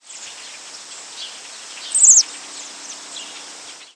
American Robin Turdus migratorius
Flight call description A high, descending, raspy "szeeert",  a piercing, trilled "szeeed", and a "szeert-szeert", occasionally followed by a short series of low "brup" notes.
Fig.1. Maryland October 2, 1994 (MO).
"Szeeert" call from bird in flight.